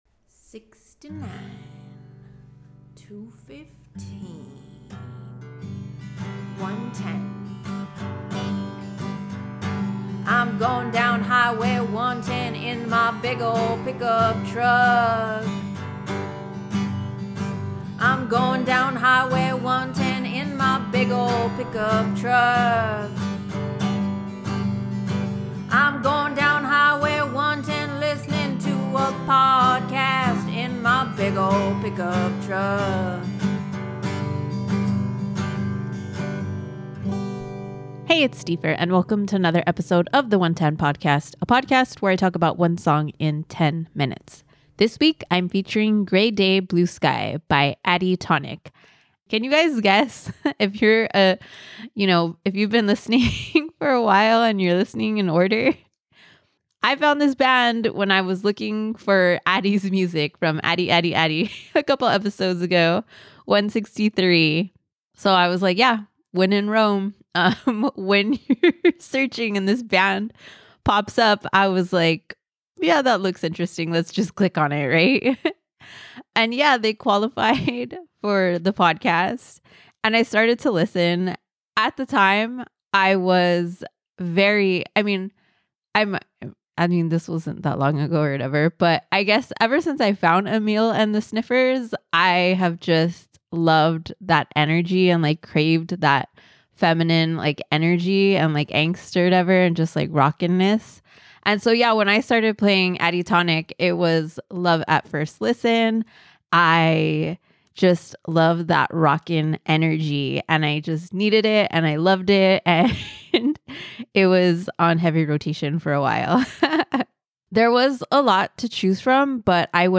I’ve been on a hard-rocking, female-fronted band kick ever since I started listening to Amyl and the Sniffers a few months ago, and Addie Tonic fit right in.